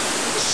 I soon got out of this, and was able to take shelter at a 24hr (deserted) BP service station just to the north of the Brisbane turnoff on the Gold Coast Hwy.
The power went out simultaneously, and was followed closely by a loud crack of thunder!
Click here to listen to this CG! (Warning, high level coarse language)